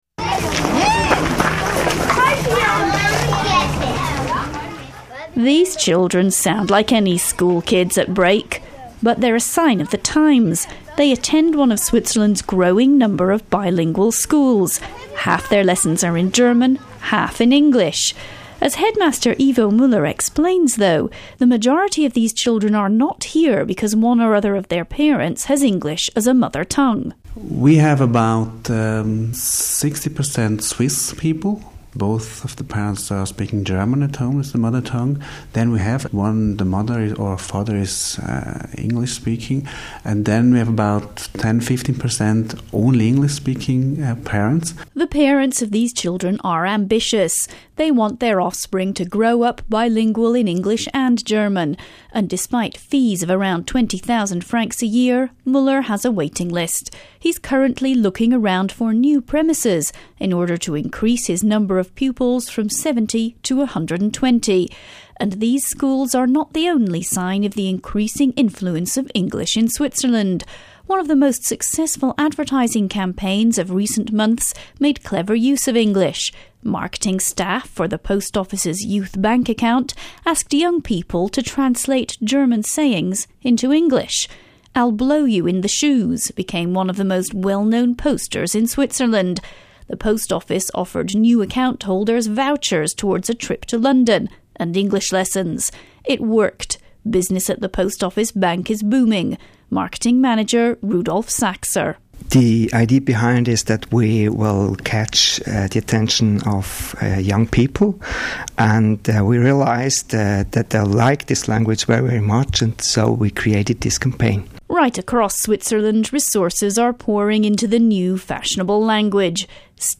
Switzerland has four national languages – French, German, Italian and Romansch (Row-man-sch) and a large proportion of the population can speak at least two of these.